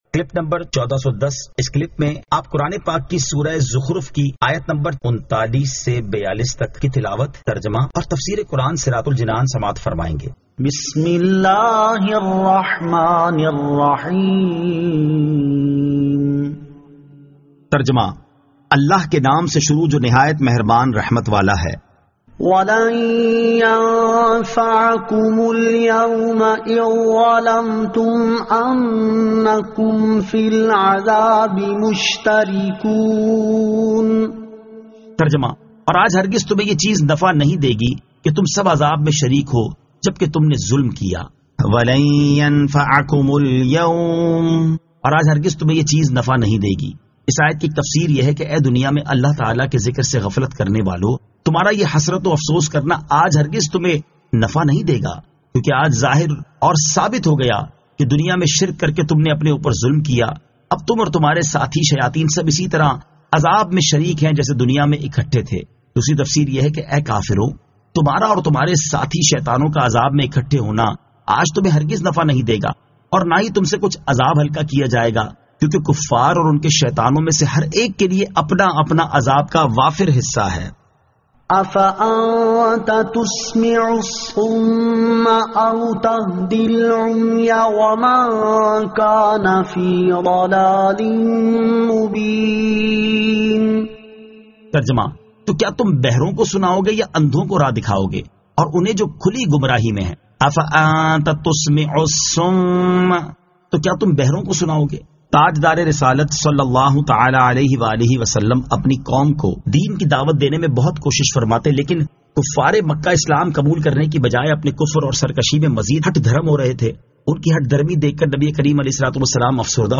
Surah Az-Zukhruf 39 To 42 Tilawat , Tarjama , Tafseer